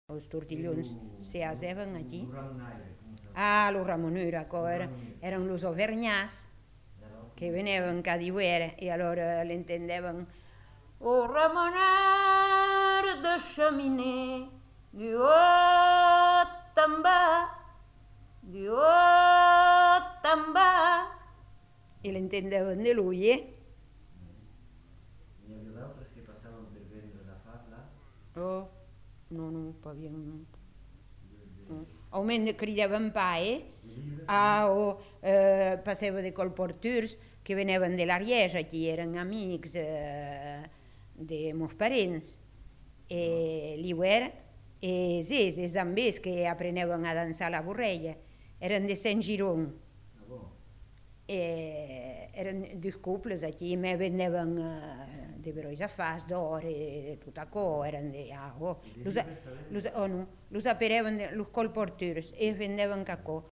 Cri de métier du ramoneur